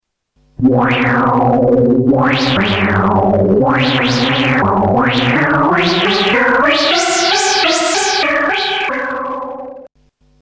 Tags: Sound Effects Orca Demos FXpansion Orca FXpansion Soft Synth